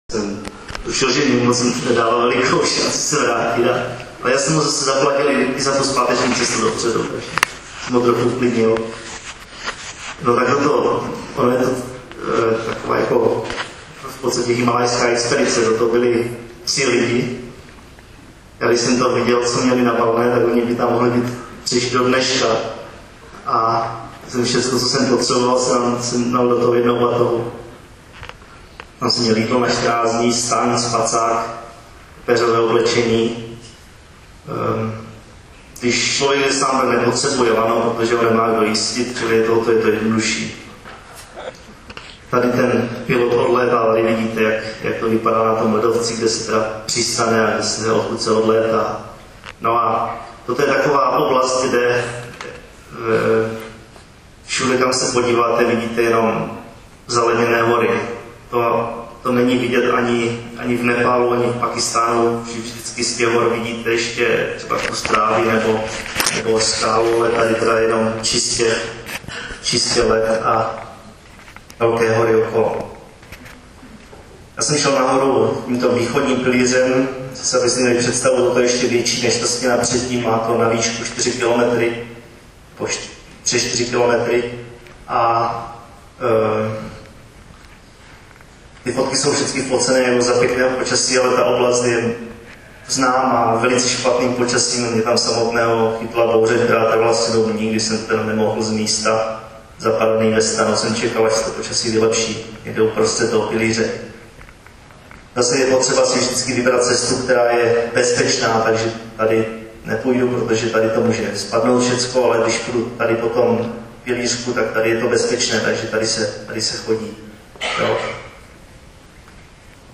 Možná i z jeho hlasu pocítíte leccos z jeho povahy. Z jeho hlasu cítíte klid a tak trochu i ironii, přestože mluví o tom, jak zcela sám zdolal - pokud se nemýlím - alpským stylem velmi náročný Cassinův pilíř na Mt. Mc Kinley na Aljašce.